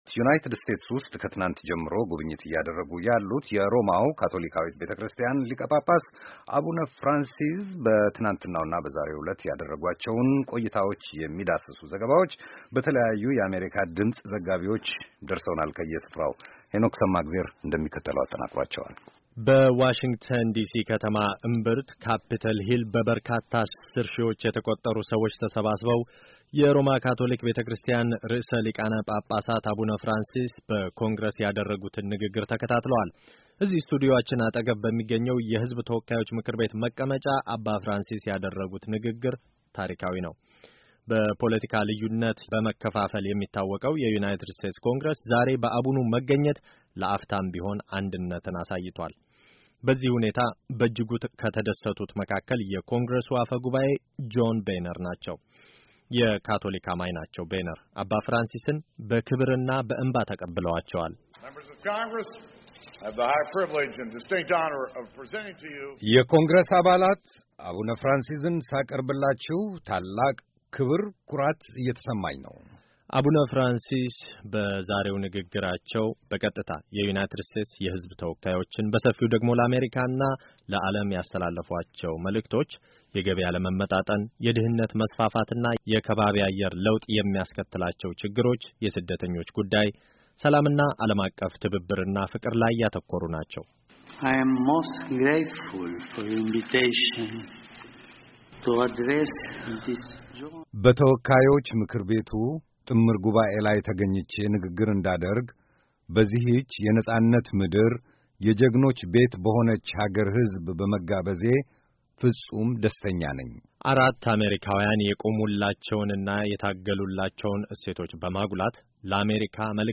ዘጋቢዎቻችን ከየሥፍራው ያጠናቀሯቸውን ዘገባ የያዘውን ቅንብር ከተያያዘው የድምፅ ፋይል ያዳምጡ፡፡